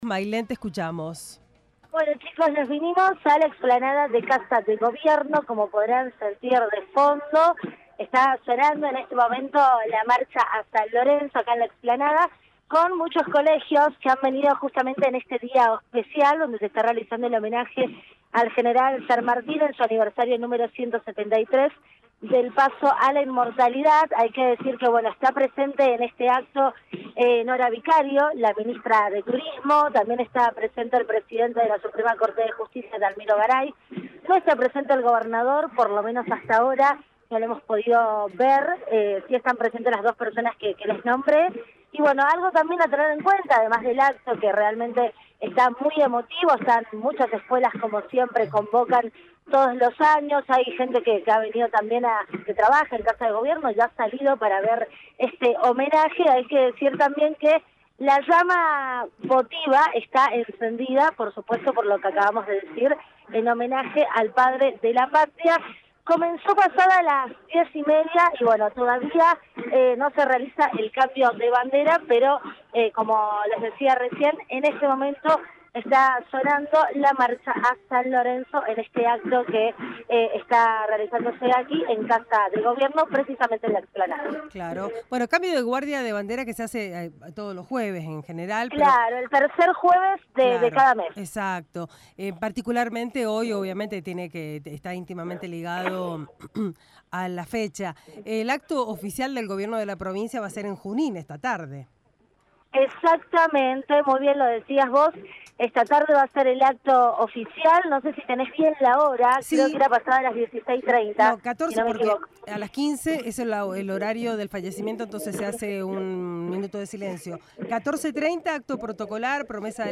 Móvil de LVDiez desde explanada Casa de Gobierno Mza